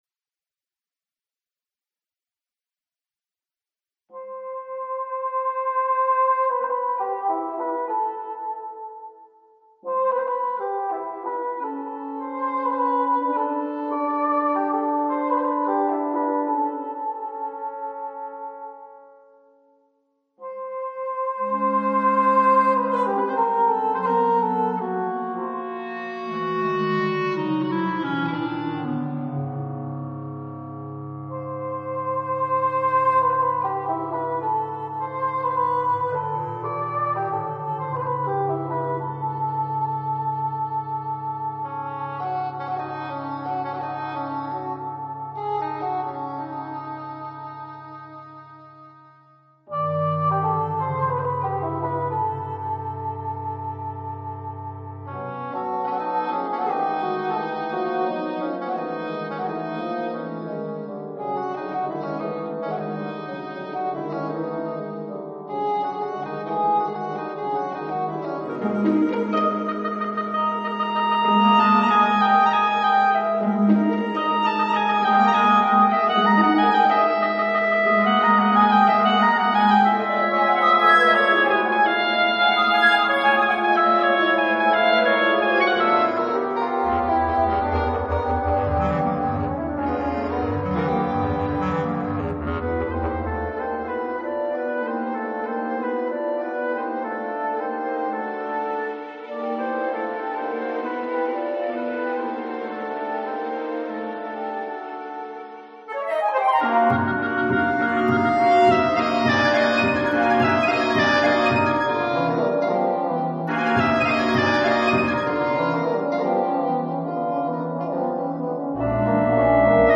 A) Musique classique : Stravinsky : Le sacre du Printemps
1. Introduction (Lento - Più mosso - Tempo I)
Ce ballet est écrit pour un orchestre symphonique exceptionnellement grand. La section de percussion est la plus importante jamais mobilisée dans un ballet.
La dominante semble être le DO, et nous pouvons alors voir la gamme de DO majeur se dessiner.
Nous avons finalement vu ici que la musique est basée sur une fondamentale : le DO, une mélodie entraînante qui revient plusieurs fois, et les gammes de DO majeure puis mineure, qui viennent être renforcées par une deuxième voix.